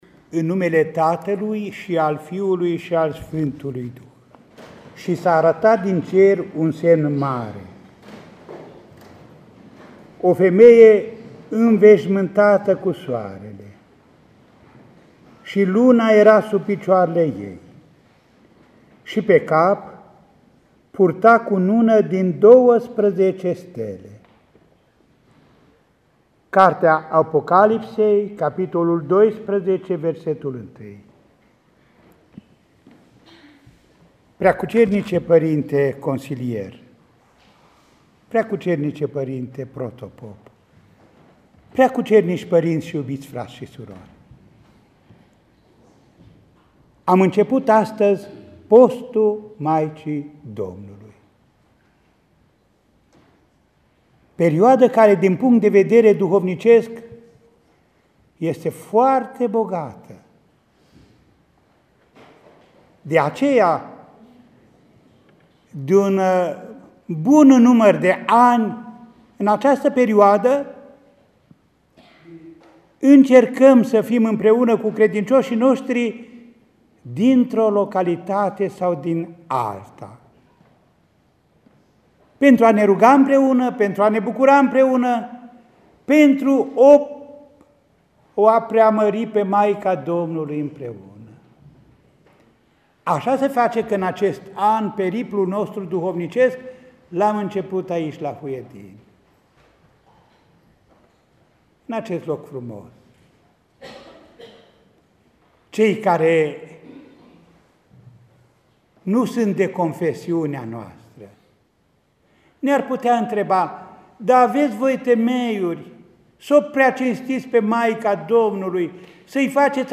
Maica Domnului este mai presus de toate creaturile văzute şi nevăzute, așa le-a spus Înaltpreasfințitul Părinte Mitropolit Andrei credincioșilor din Parohia „Sfânta Treime” din Huedin.
În cuvântul de învățătură, rostit după Paraclisul Maicii Domnului, Înaltpreasfinția Sa le-a prezentat trei dintre motivele pentru care creștinii ortodocși o preacinstesc pe Maica Domnului: